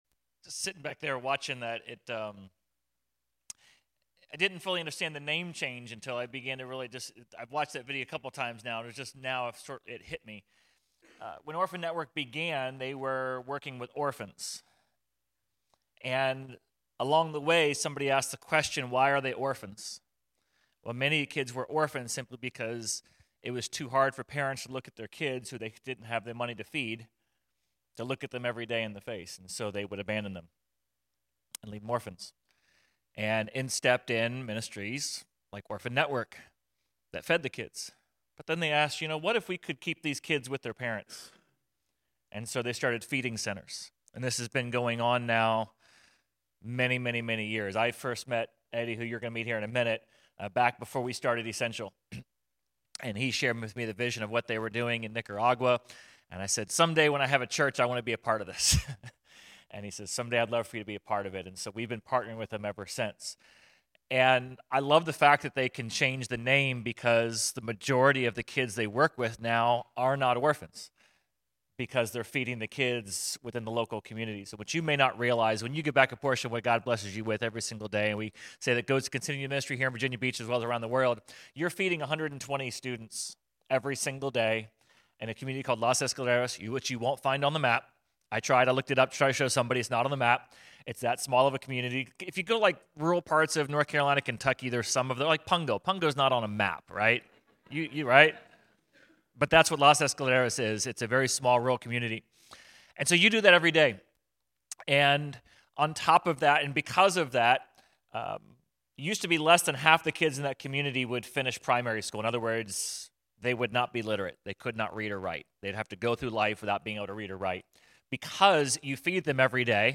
Sermon_3.15.26.mp3